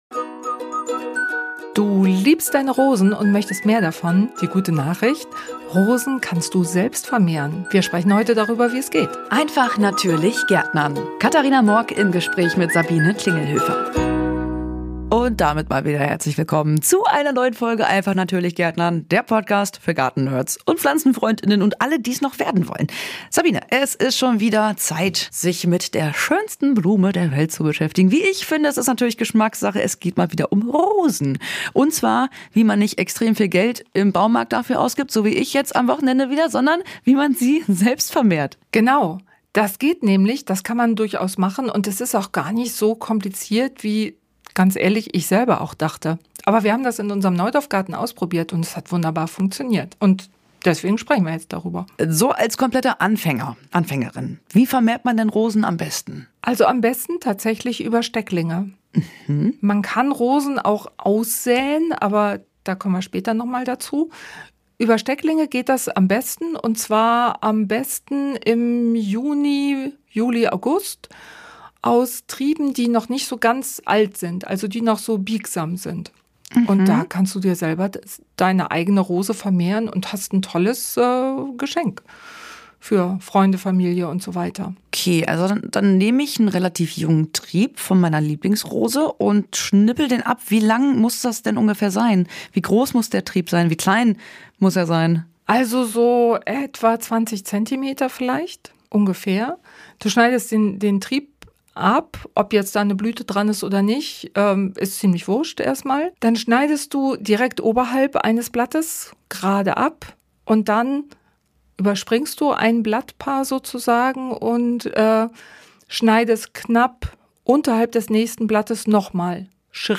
Hier geht es um das praktische und einfache Gärtnern mit der Natur für Gartenanfänger und auch für Fortgeschrittene. Im Gespräch stellen wir einfache Möglichkeiten vor, wie man selbst kompostieren kann, oder wie man am einfachsten ein eigenes Gemüsebeet anlegt.